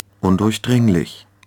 Aussprache:
🔉[ˈʊn…]